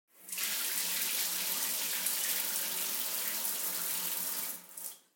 دانلود آهنگ آب 51 از افکت صوتی طبیعت و محیط
جلوه های صوتی
دانلود صدای آب 51 از ساعد نیوز با لینک مستقیم و کیفیت بالا